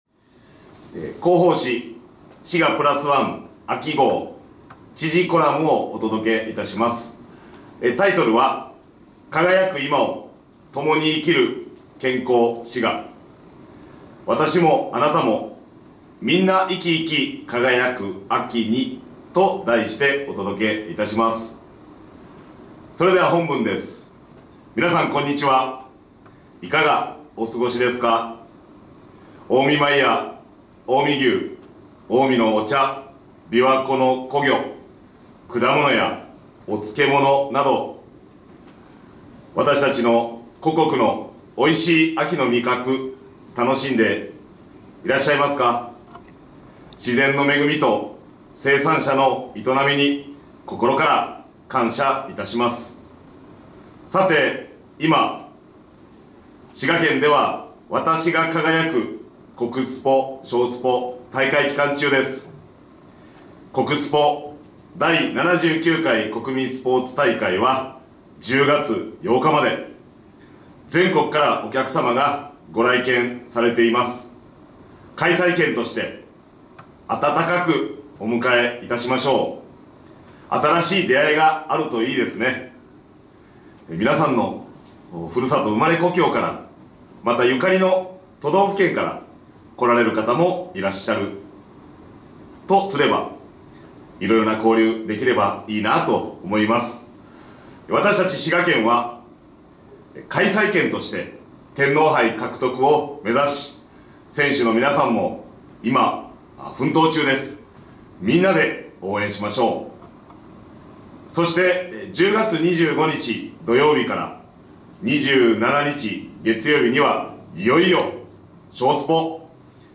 みんなでプラスワン！ vol.53 P15 (PDF:485 KB) 【音声版】みんなでプラスワン（知事朗読）Vol.53 (mp3:4 MB) ほっとサロン 「滋賀プラスワン」秋号へのご感想や県政へのご意見などをお寄せいただいた方の中から抽選でプレゼントが当たります！